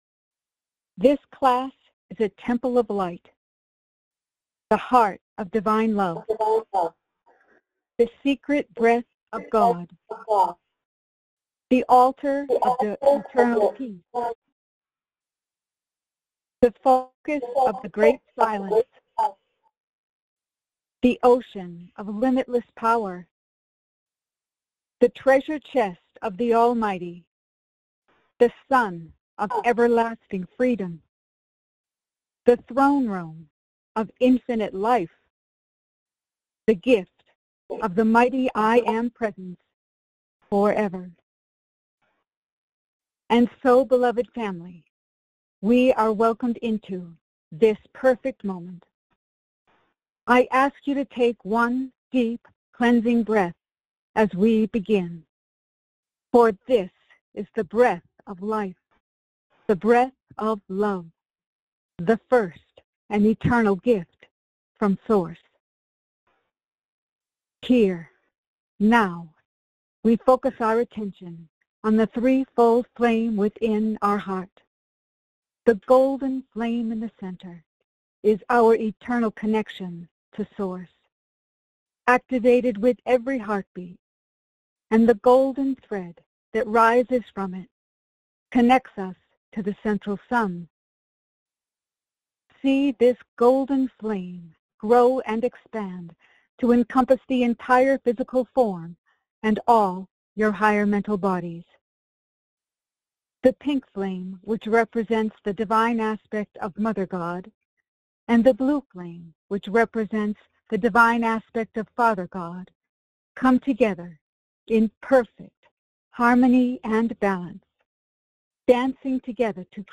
These messages were given during our Ancient Awakenings weekly Sunday conference call in Payson, AZ on January 4, 2026.